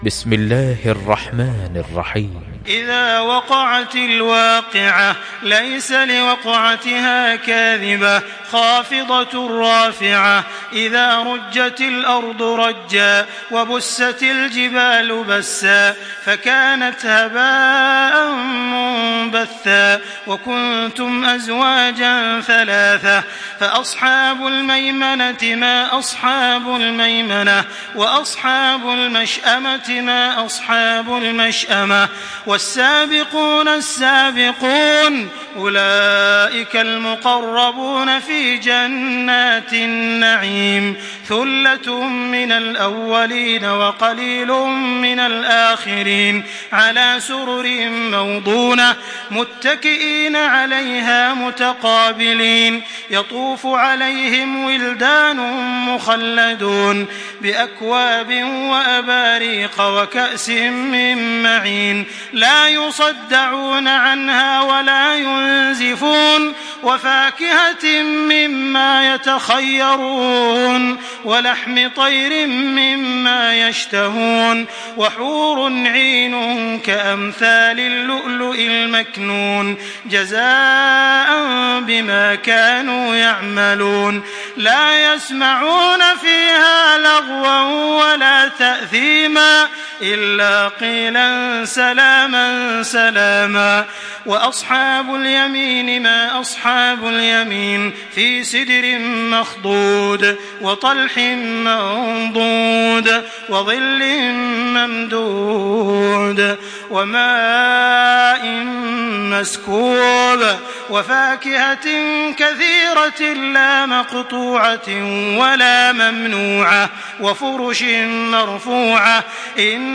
Surah Al-Waqiah MP3 in the Voice of Makkah Taraweeh 1425 in Hafs Narration
Listen and download the full recitation in MP3 format via direct and fast links in multiple qualities to your mobile phone.
Murattal